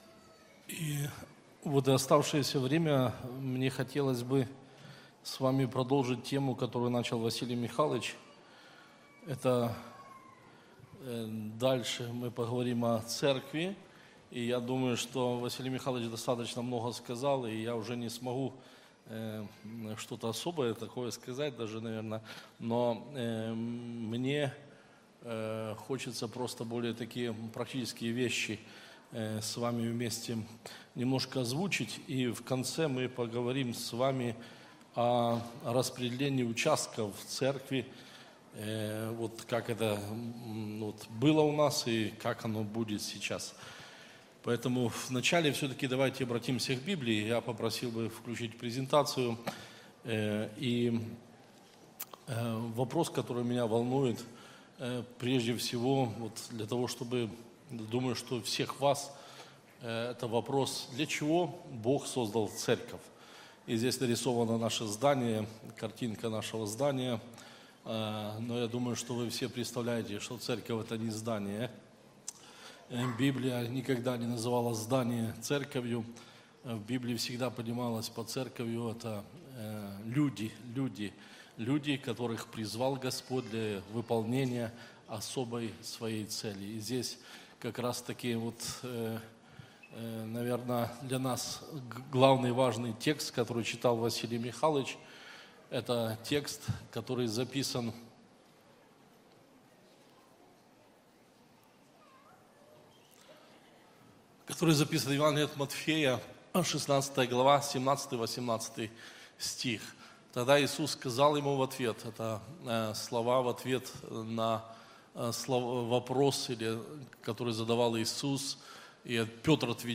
Church4u - Проповеди